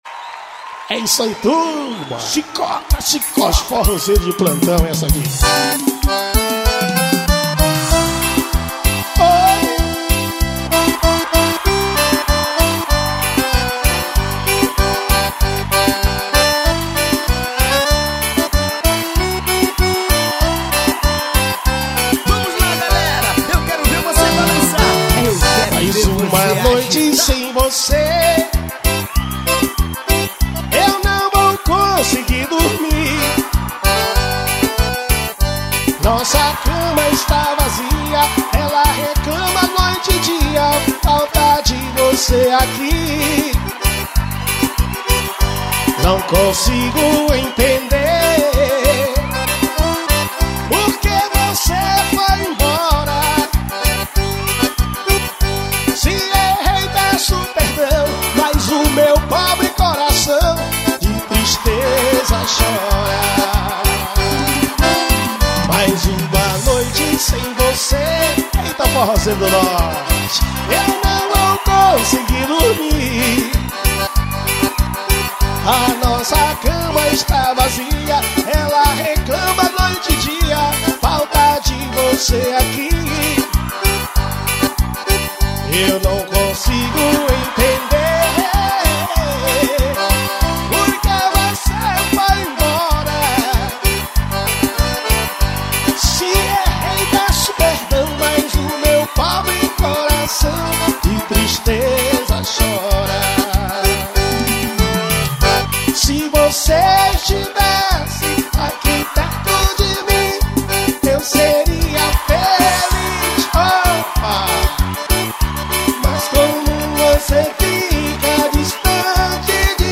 AO VIVO SHOW GOIANIA.